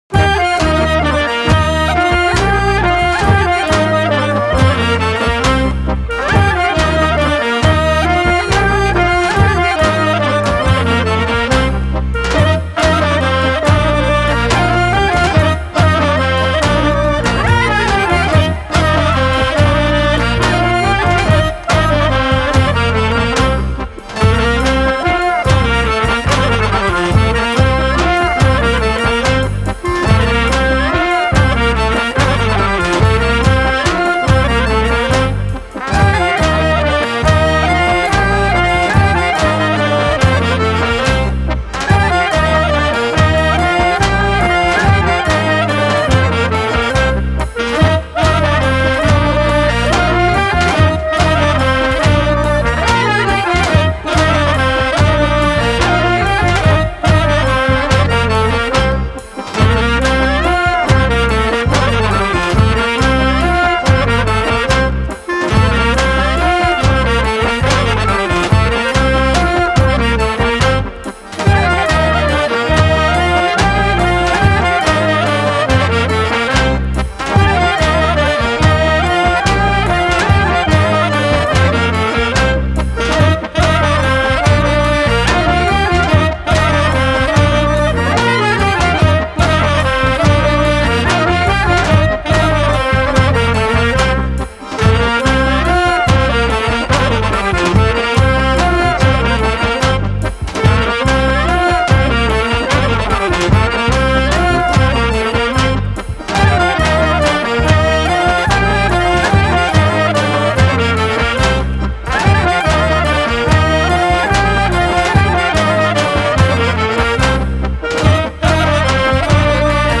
со оркестар